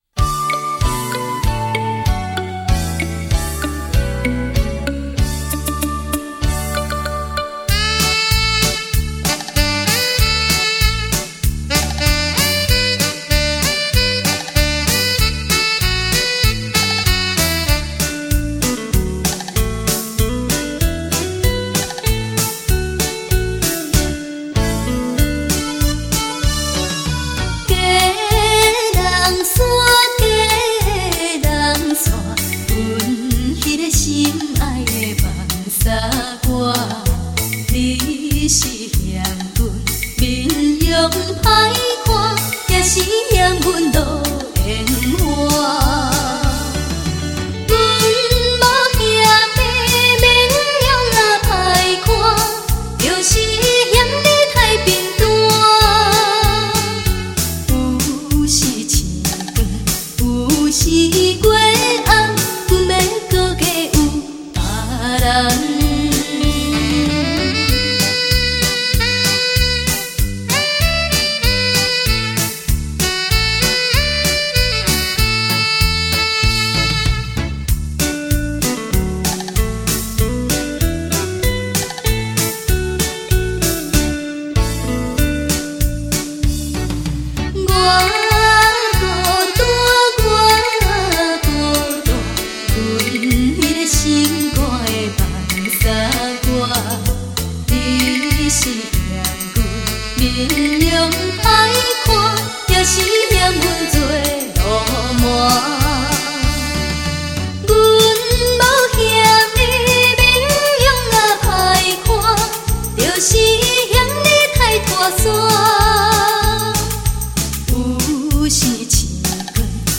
重新编曲 全新演唱
女声实力歌手 保证让您百听不厌
世纪末台语情歌代表作